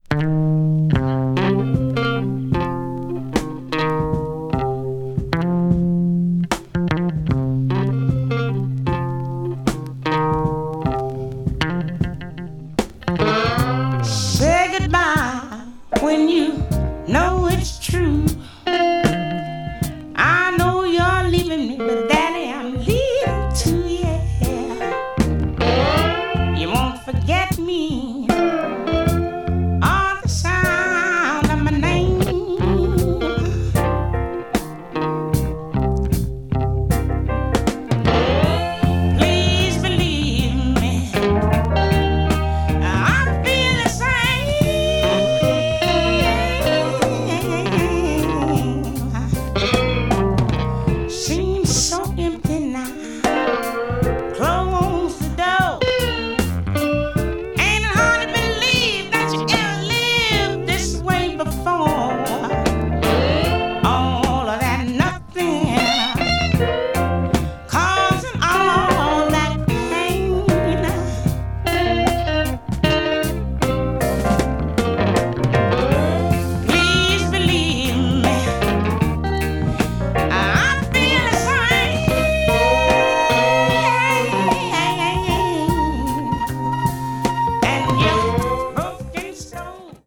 とにかく素晴らしい音質。
メリハルのある音質が秀逸な国内盤です。
blues jazz   jazz funk   jazz vocal   soul jazz